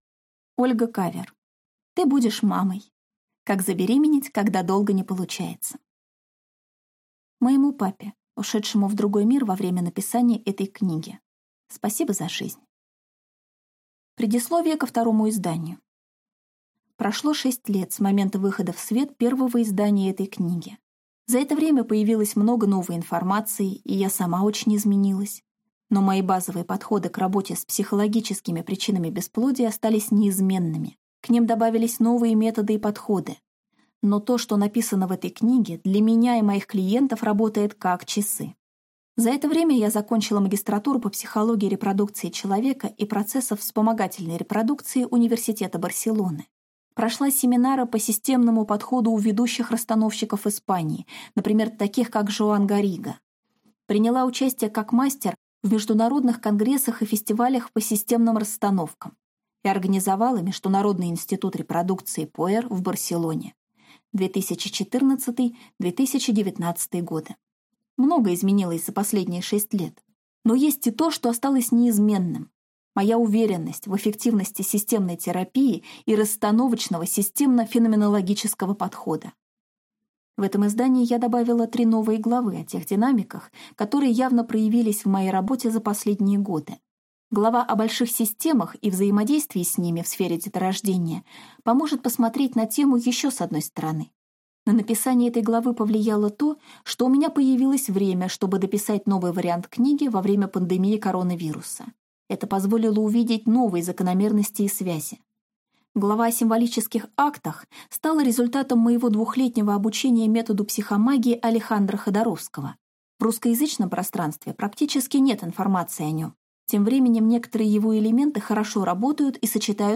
Аудиокнига Ты будешь мамой! Как забеременеть, если долго не получается | Библиотека аудиокниг